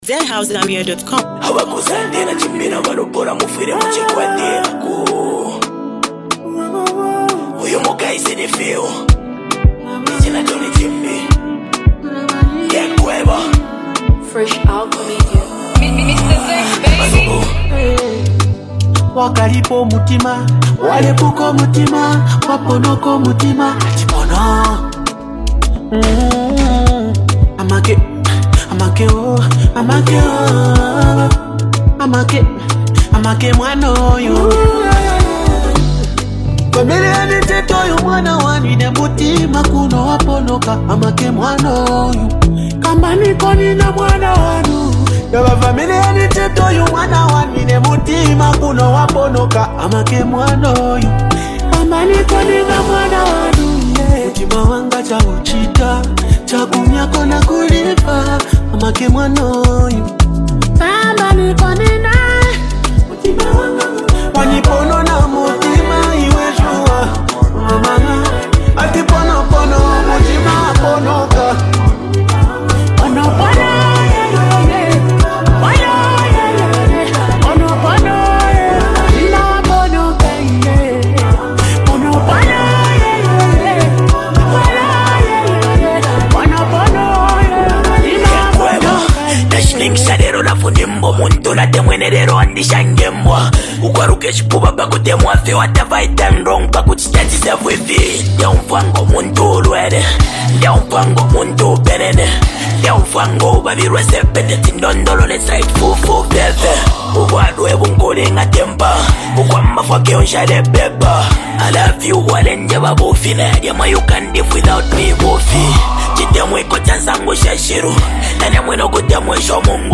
It’s the perfect tune to relax, vibe, and sing along.